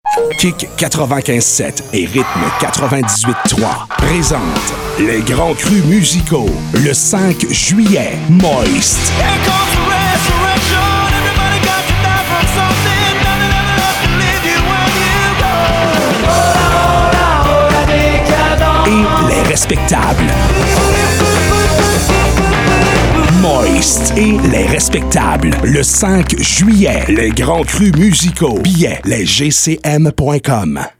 PROMO RADIO MOIST & LES RESPECTABLES
promo-radio-les-grands-crus-5-juillet-2025.mp3